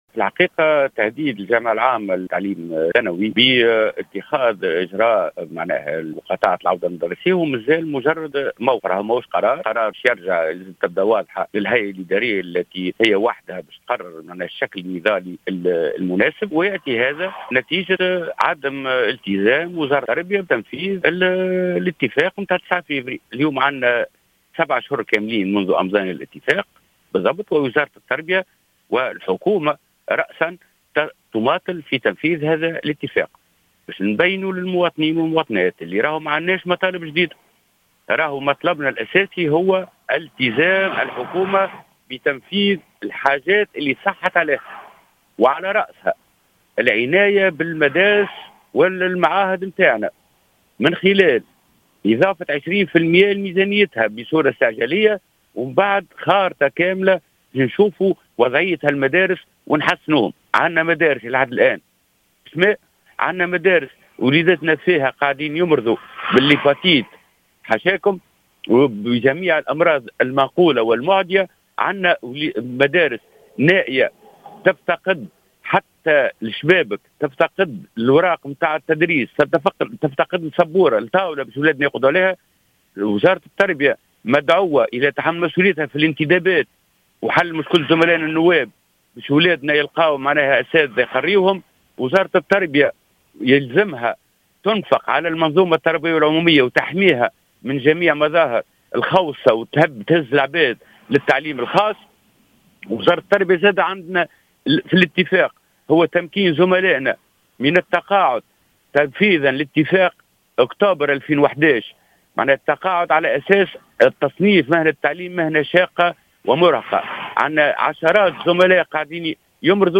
في تصريح للجوهرة "اف ام"